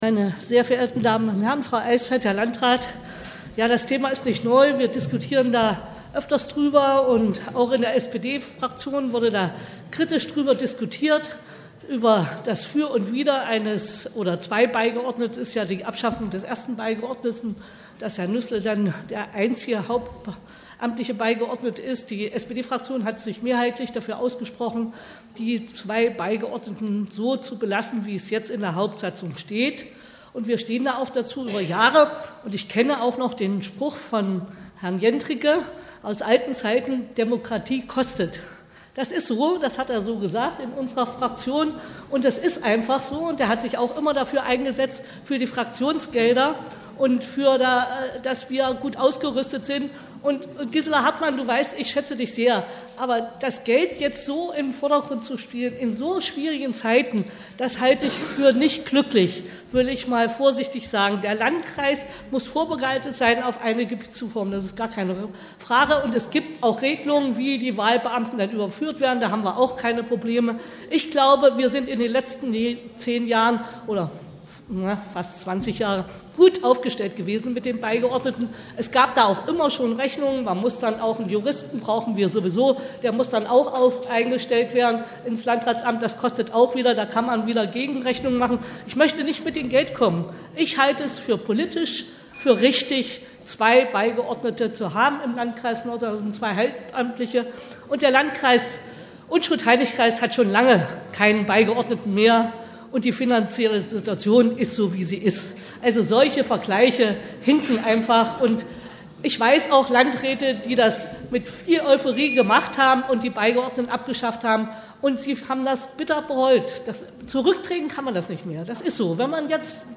Wir haben hier die Wortmeldungen als Audiobeiträge für Sie zusammengestellt, die uns freundlicherweise das Bürgerradio ENNO zur Verfügung gestellt hat.